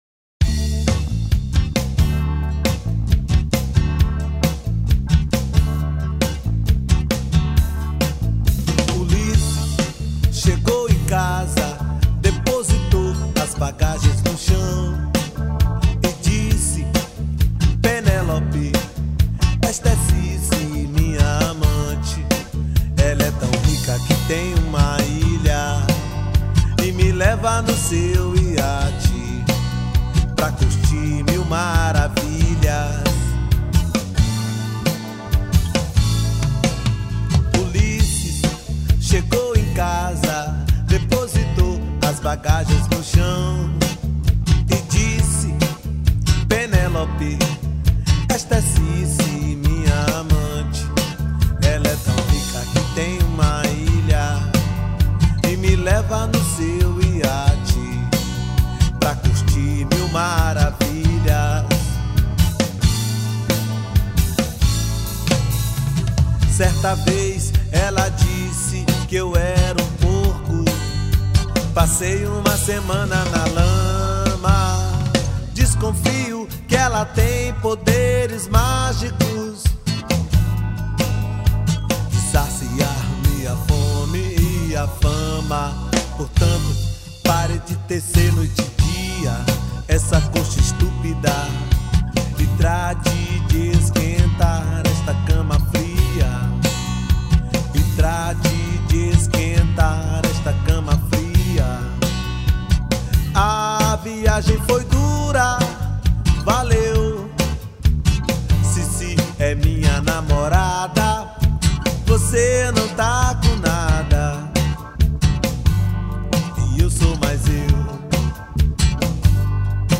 2612   02:04:00   Faixa: 4    Reggae